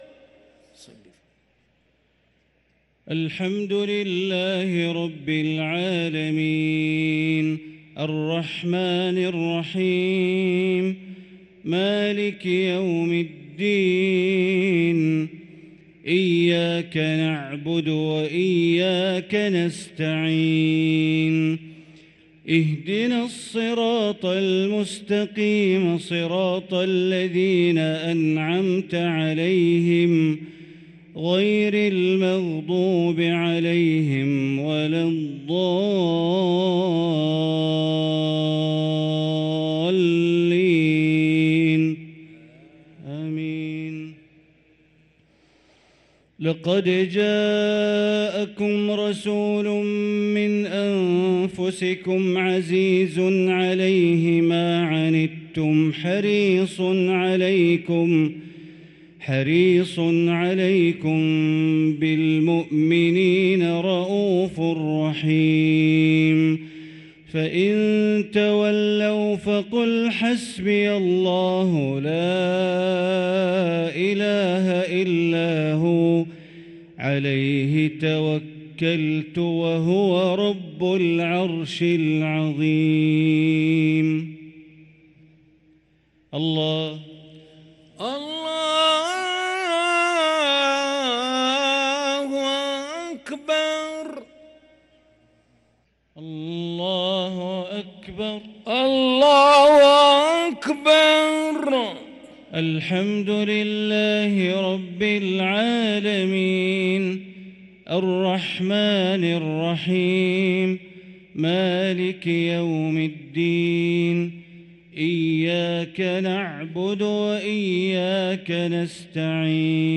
صلاة العشاء للقارئ بندر بليلة 15 رمضان 1444 هـ